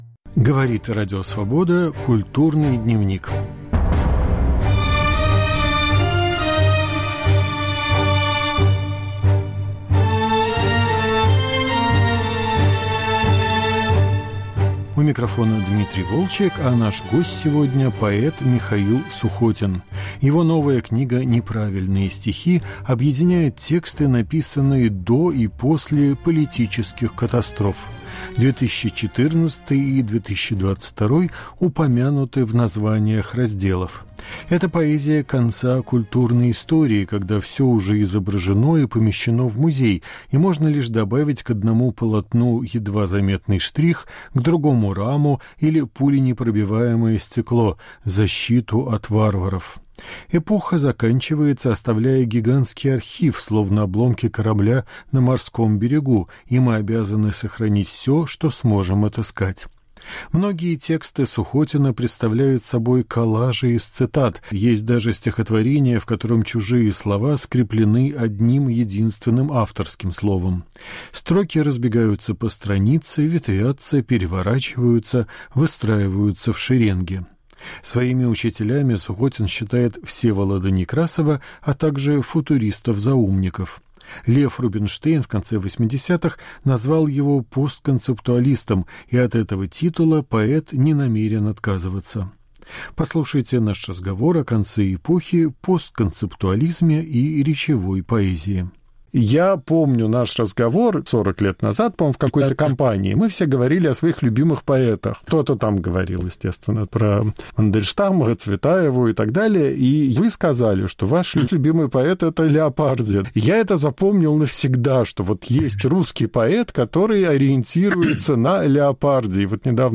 Разговор о новой книге "Неправильные стихи"